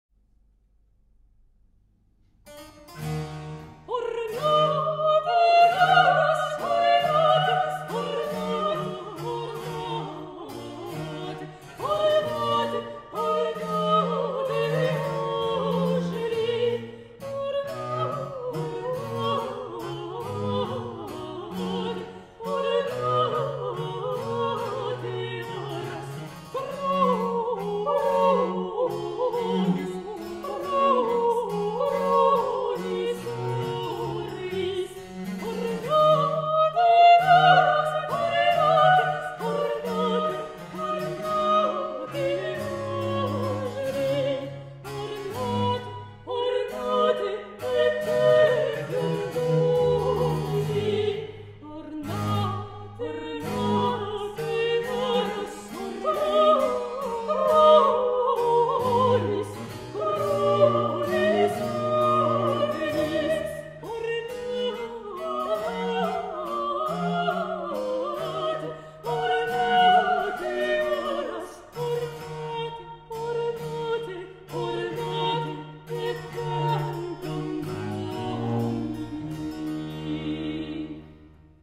basse de viole
violons.